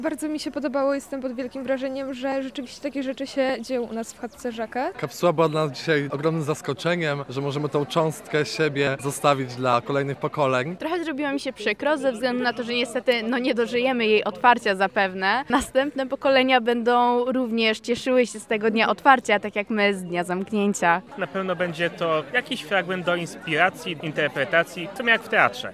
Oto jakie emocje odczuwali uczestnicy ceremonii:
SONDA